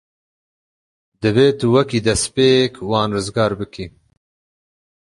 Pronúnciase como (IPA) /dɛstˤˈpˤeːkˤ/